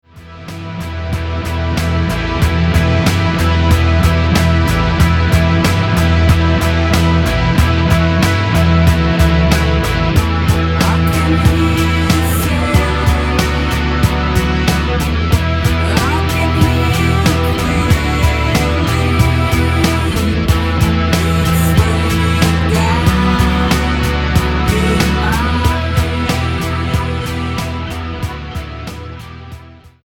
The results cohere into a sonic juggernaut.